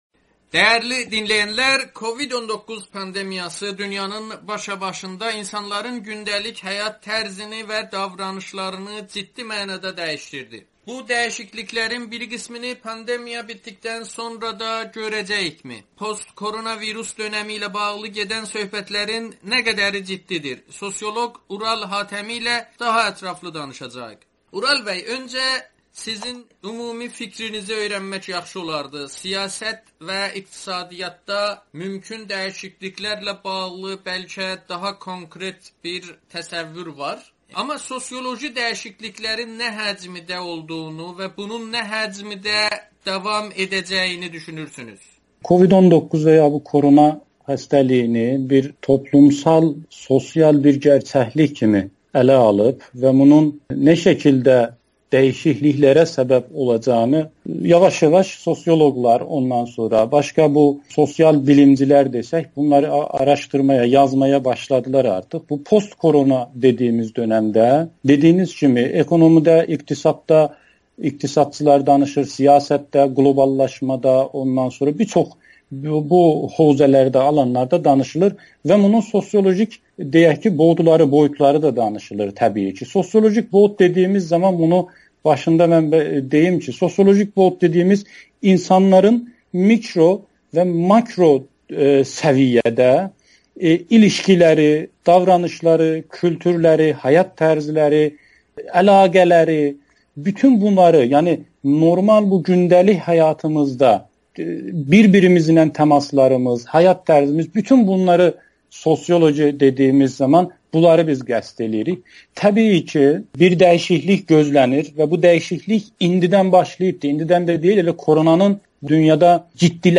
Amerikanın Səsi ilə söhbətdə COVID-19 pandemiyasının cəmiyyətlərə müxtəlif təsirlərini şərh edir.